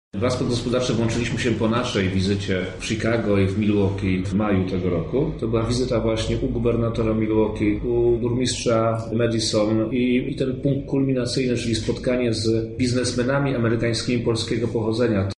-dodaje Czarnek.